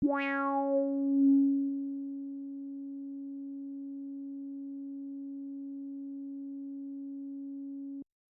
标签： MIDI-速度-16 CSharp5 MIDI音符-73 赤-AX80 合成器 单票据 多重采样
声道立体声